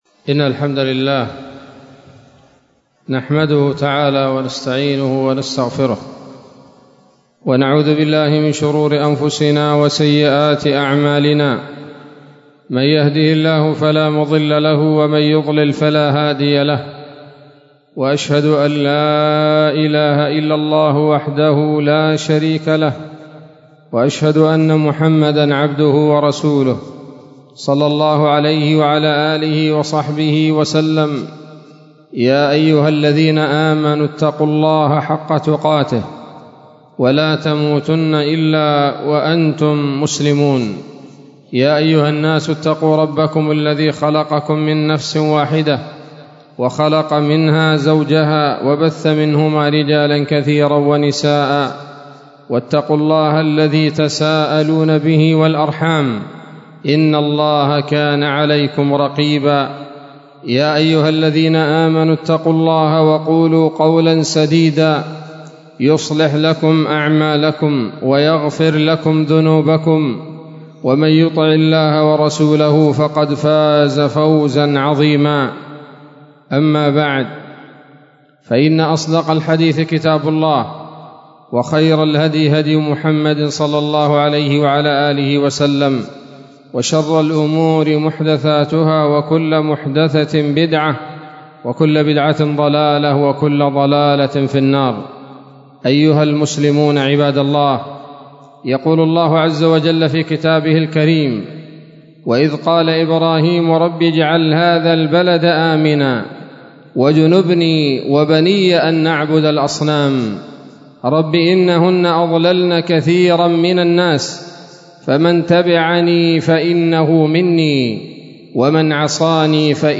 خطبة جمعة بعنوان